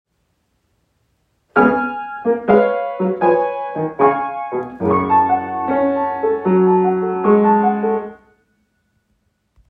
Example 2.3.2. Enharmonic equivalents: Le Beau[2], Piano Sonata, Op. 8, i –Allegro ma non troppo